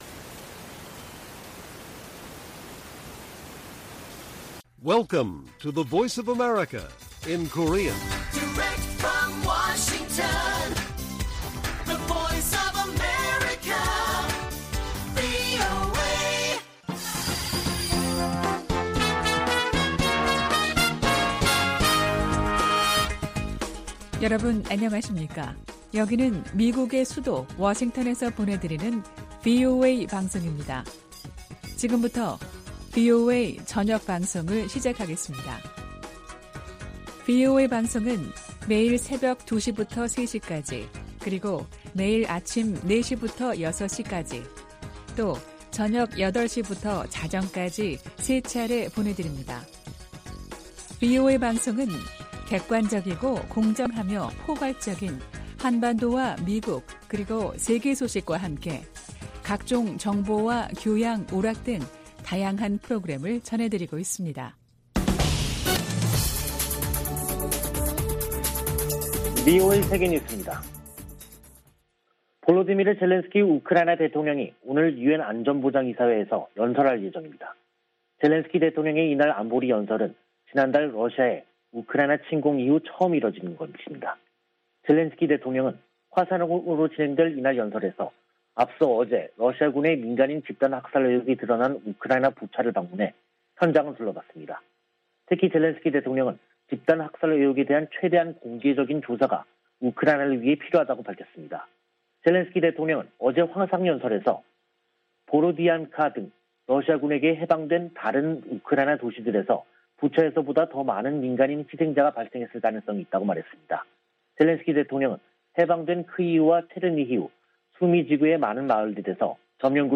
VOA 한국어 간판 뉴스 프로그램 '뉴스 투데이', 2022년 4월 5일 1부 방송입니다. 미 국무부는 한국의 윤석열 차기 정부가 전략동맹 강화를 언급한 것과 관련해 “한국은 중요한 조약 동맹”이라고 말했습니다. 미·한 북핵대표가 북한의 최근 ICBM 발사에 대한 새 유엔 안보리 결의안 추진 의사를 밝혔습니다.